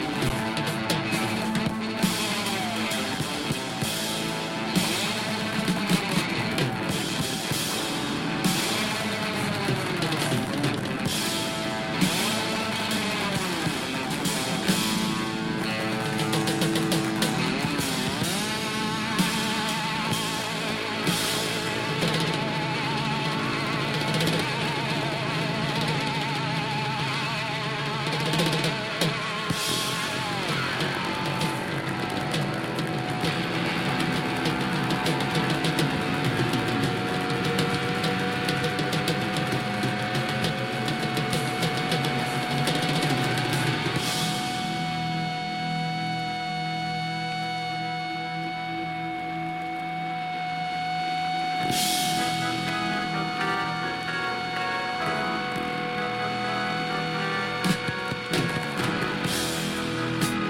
The first release pairs a couple of prime library tracks
grungy